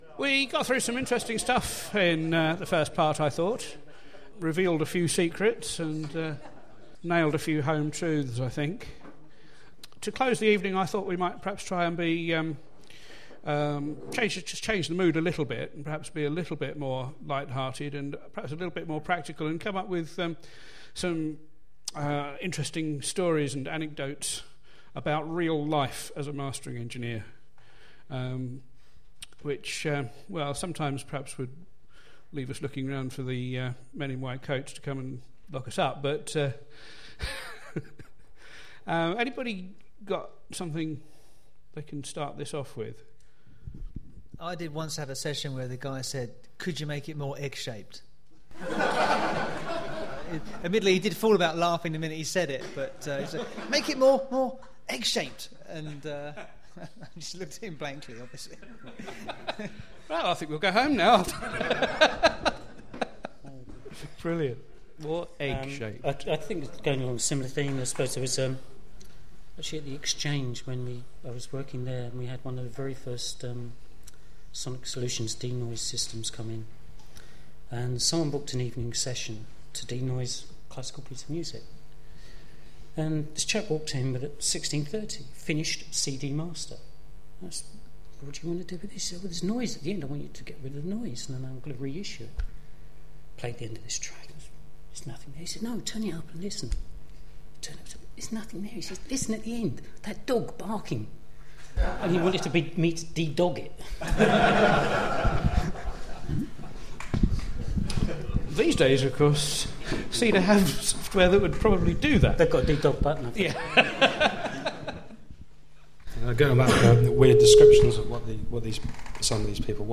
For our sixth event we moved to Metropolis Studios for an evening devoted to Mastering for vinyl, CD and beyond
Our panel consisted of